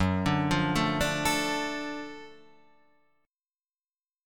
F#mM7#5 Chord